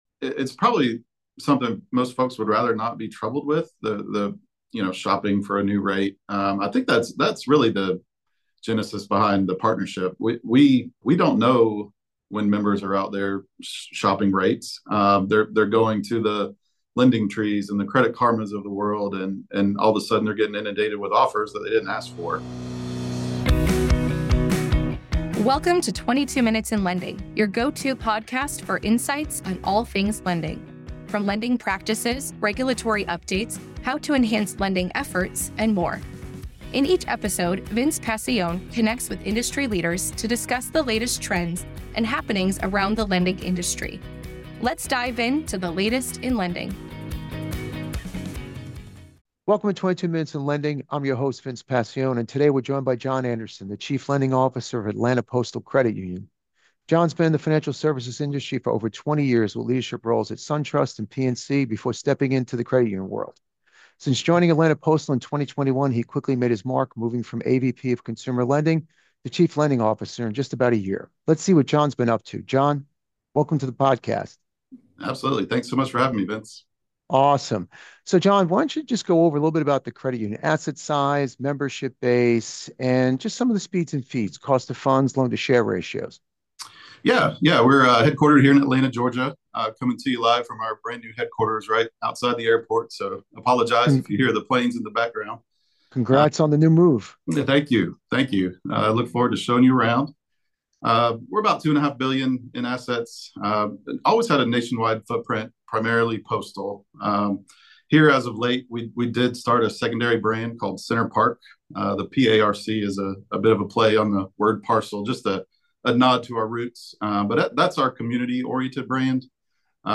Play Rate Listened List Bookmark Get this podcast via API From The Podcast 22 Minutes in Lending is a podcast that brings you leading conversations on lending.